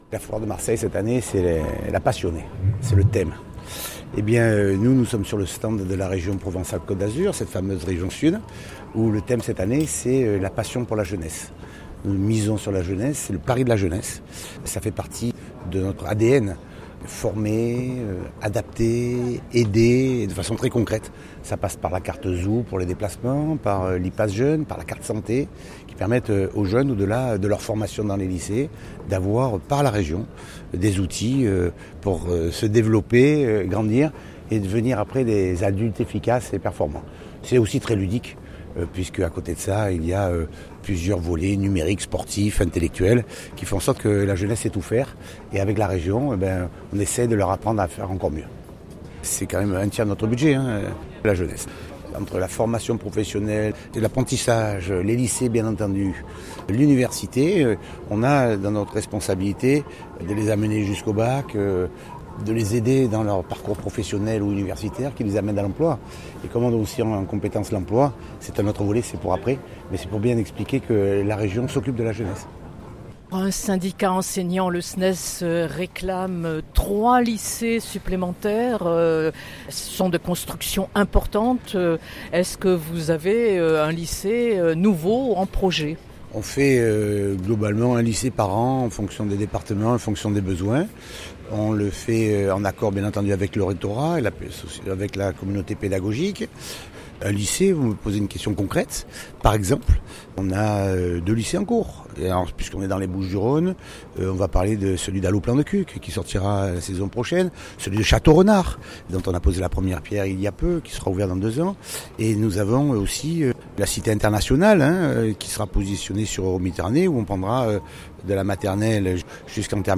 Entretien.
renaud_muselier_stand_foire_21_09_2018.mp3